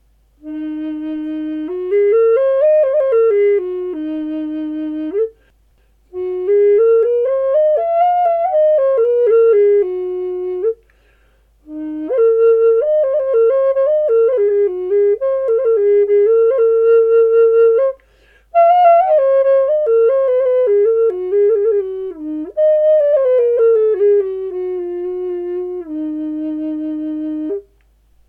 Eb4 (D#5) in Primavera with Black Walnut bird (very light weight) ADOPTED!